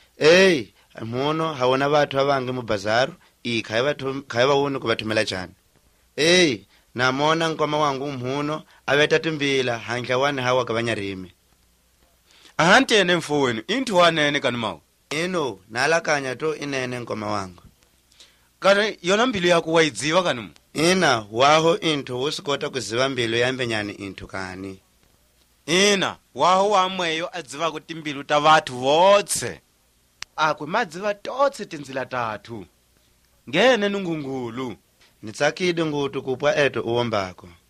And the only instance of a nasal vowel, in a word which sounds like “également”, makes me think that the language is spoken in a region that used to be a French colony, i.e. Togo, Cameroon and the like.
3 November 2013 at 10:13 pm I think I hear terraced tones.